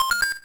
laser_ready.ogg